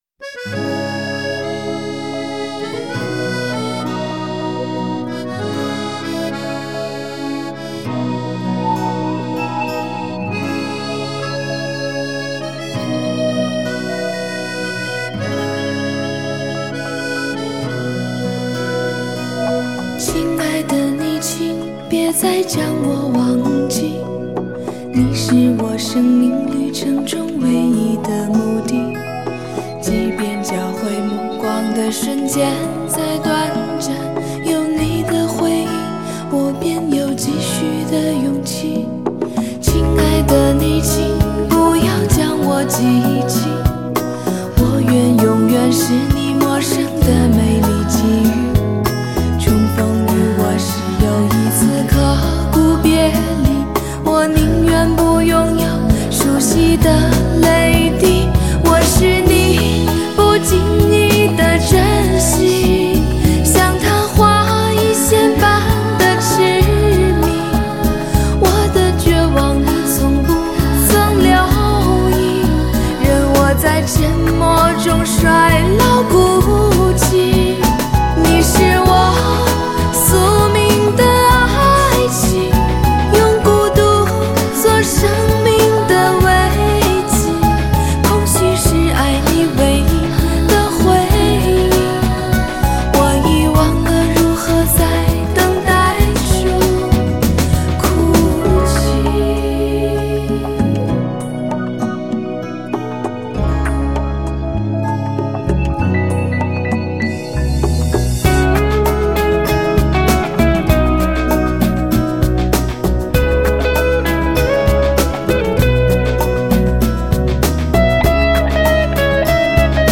清澈旋律，沉淀在一代代人的心中，并不断拨动着心灵的琴弦，这就是校园歌曲，我们的纯真年代，荡漾着初恋的回味。